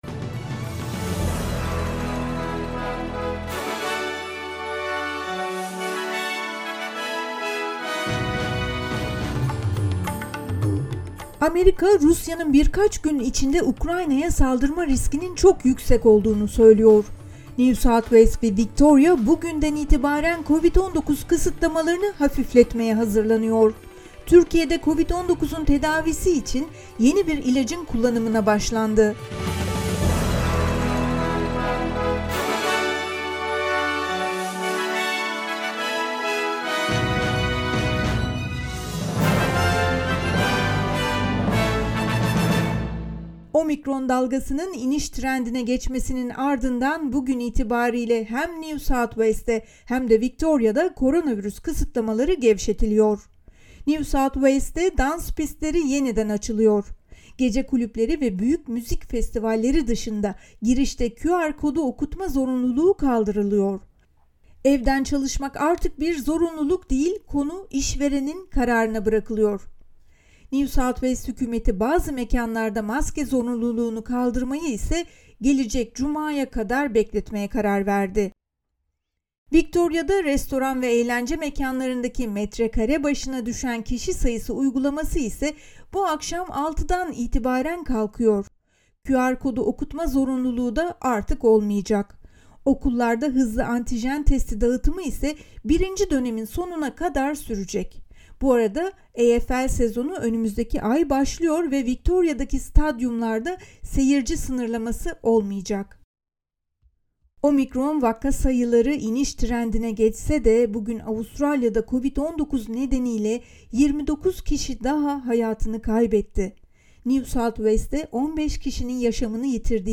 SBS Türkçe Haber Bülteni 18 Şubat